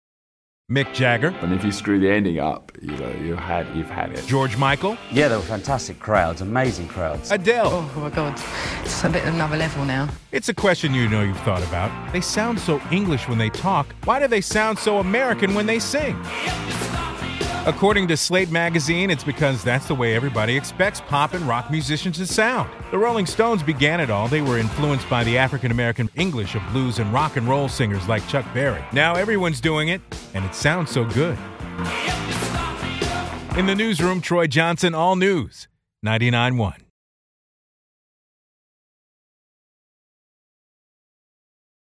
A report I filed on WNEW. A linguist has figured it all out...